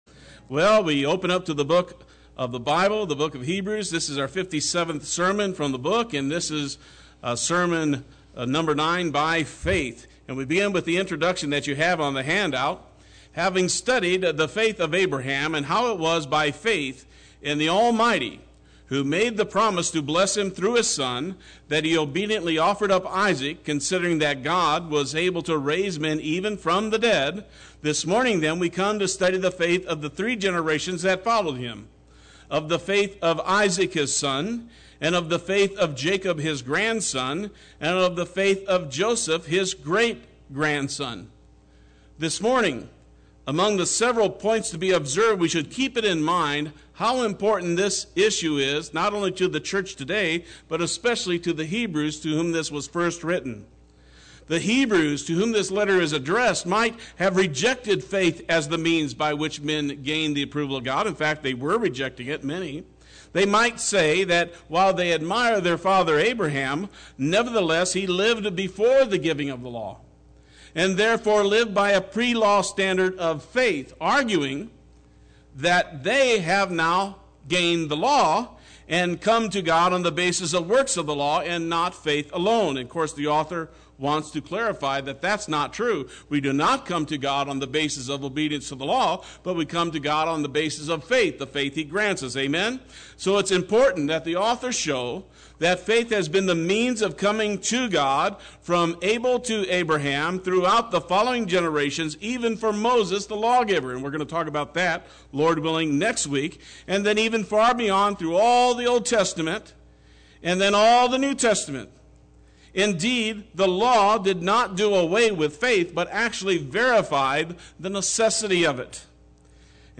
Play Sermon Get HCF Teaching Automatically.
Part 9 Sunday Worship